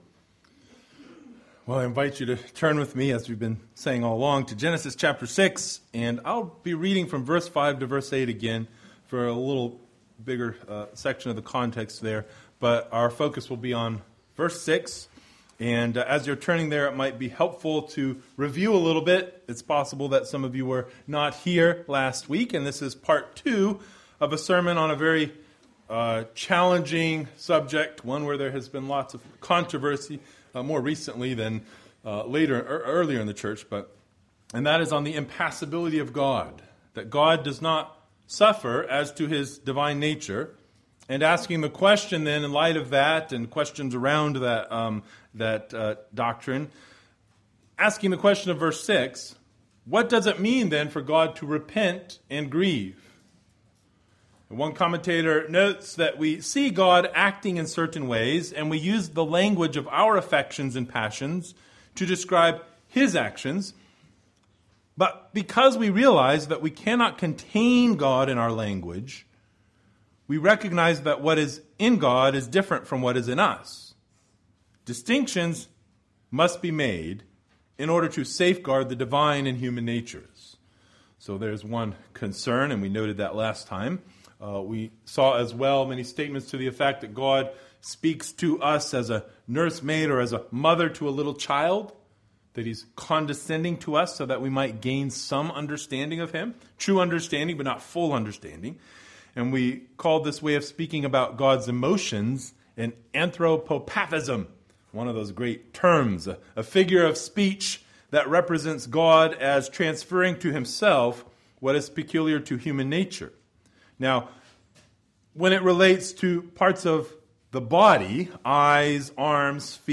Passage: Genesis 6:5-8 Service Type: Sunday Morning